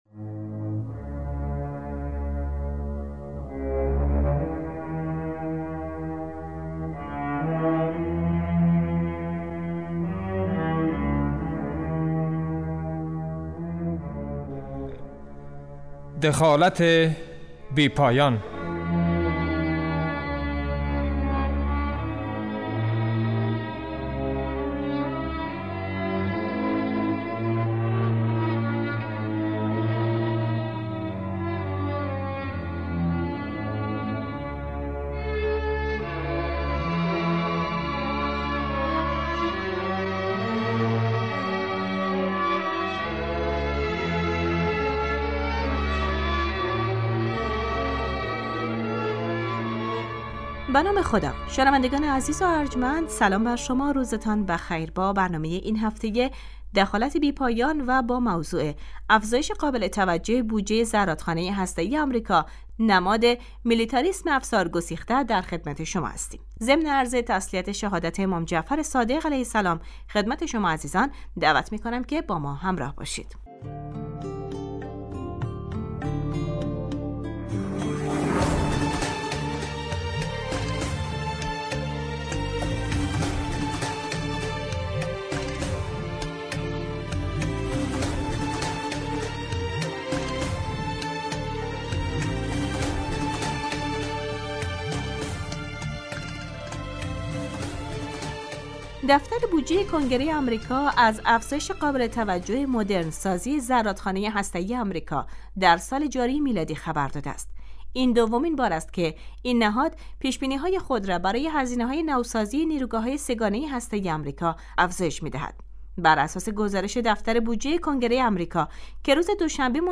دخالت بی پایان عنوان برنامه 15 دقیقه ای است که در روزهای شنبه ساعت 14:15 پخش می شود .دراین برنامه به موضوع آمریکا و دخالتهای بی پایان آن در امور اقتصادی ،...